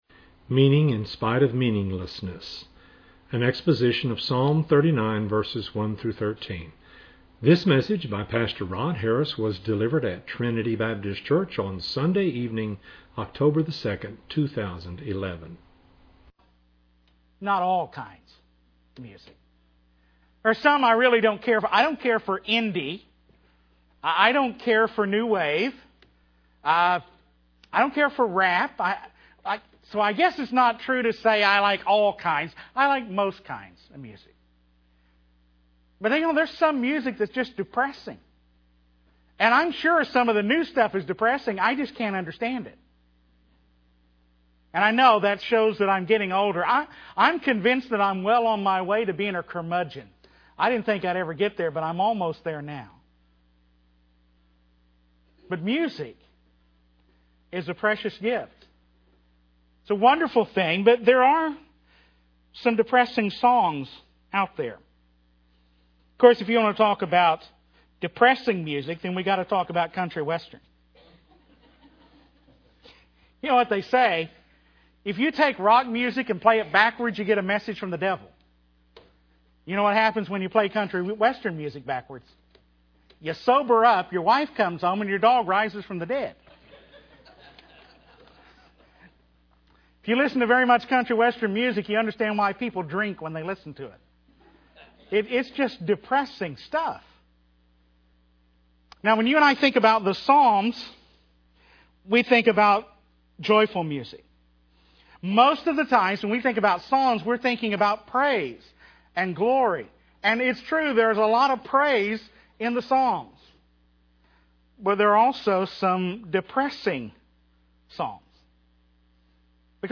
delivered at Trinity Baptist Church on Sunday evening